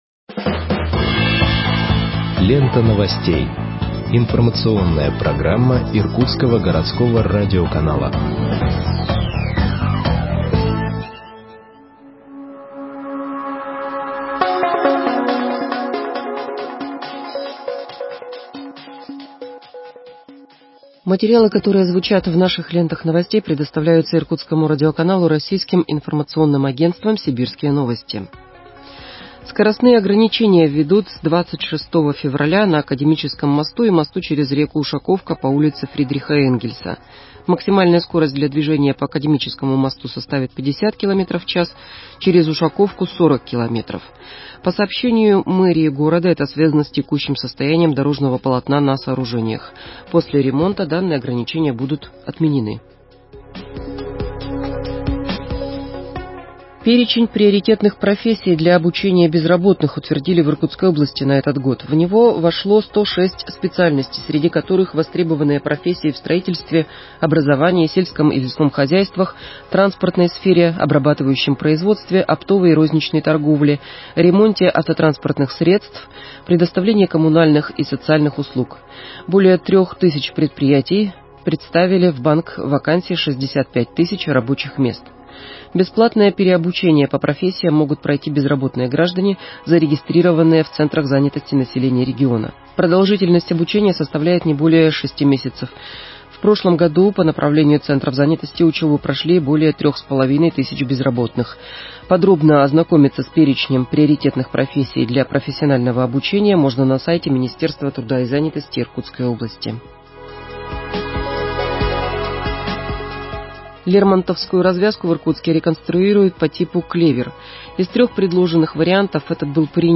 Выпуск новостей в подкастах газеты Иркутск от 16.02.2021 № 1